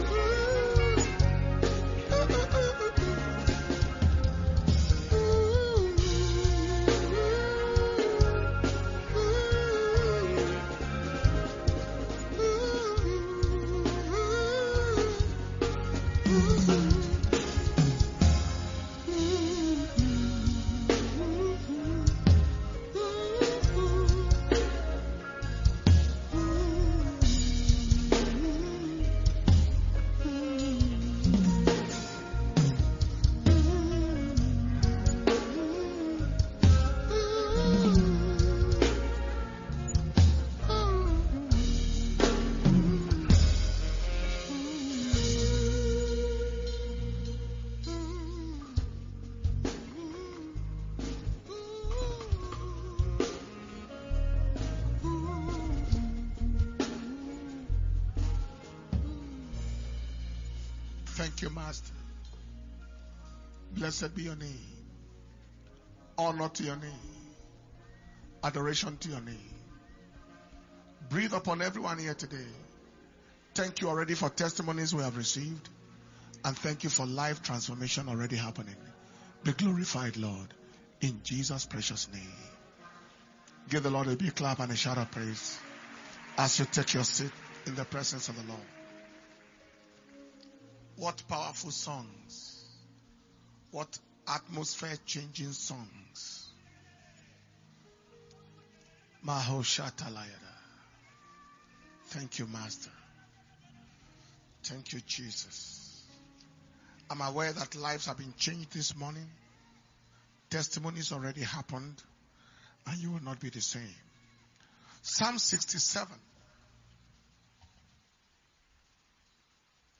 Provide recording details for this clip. December 2022 Blessing Sunday Service – Sunday, 4th December 2022